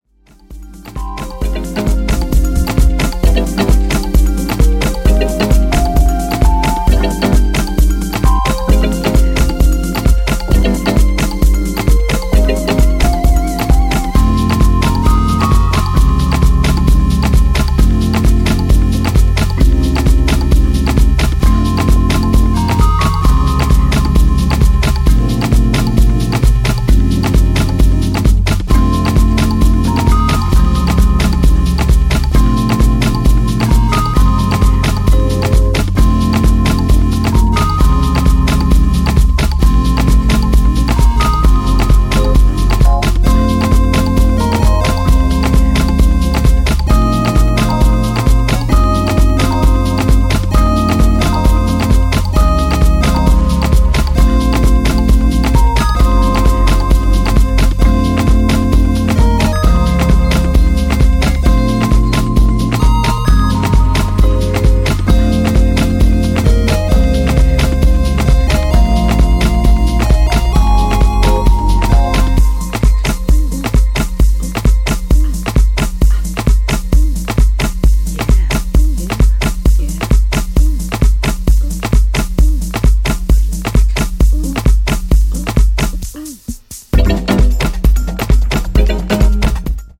よりハウシーなテンポでアナログシンセやマリンバ、パーカッションの艶やかな響きと共に熱く感傷的な起伏を描く